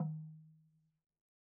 LogDrumHi_MedM_v1_rr2_Sum.wav